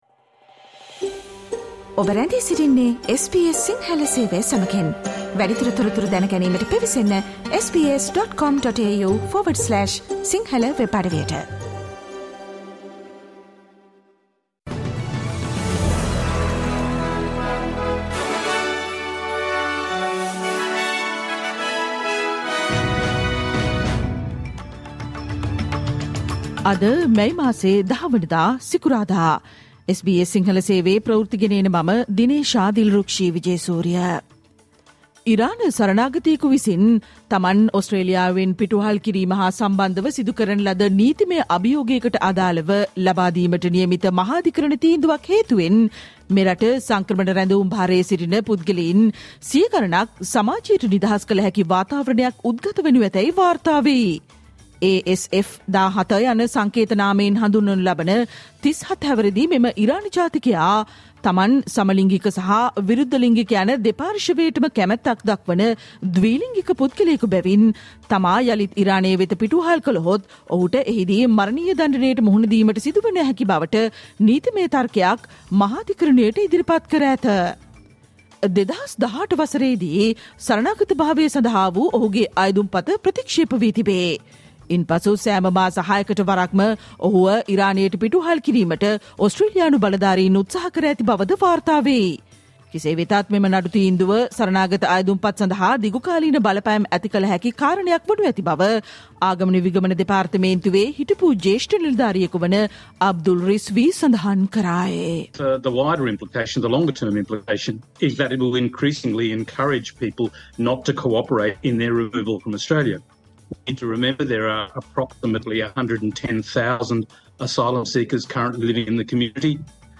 Australian news in Sinhala, foreign and sports news in brief - listen, Sinhala Radio News Flash on Friday 10 May 2024